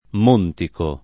montico [ m 1 ntiko ], -chi